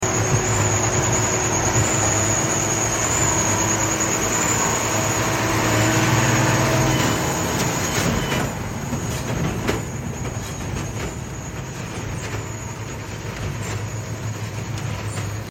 Fiat 180 90 beim Stroh pressen. sound effects free download
Mp3 Sound Effect Fiat 180-90 beim Stroh pressen.